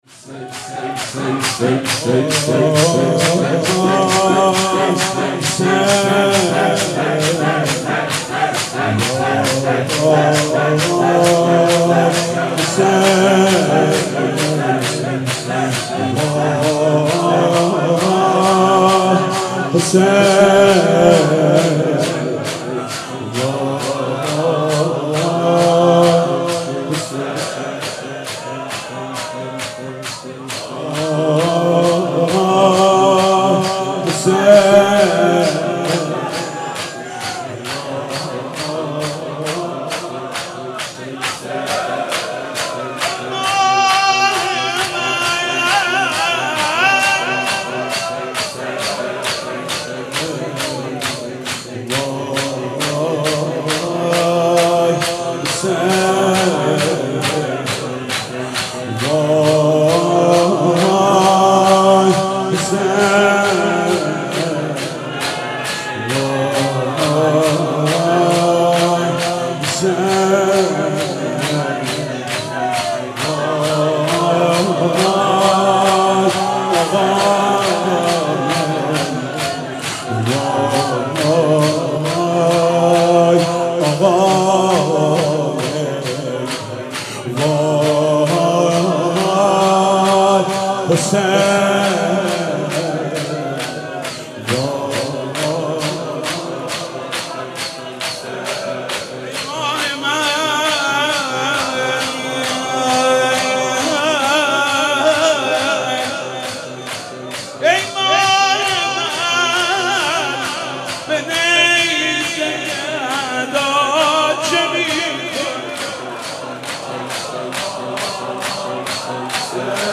مناسبت : وفات حضرت ام‌البنین سلام‌الله‌علیها
مداح : محمود کریمی قالب : شور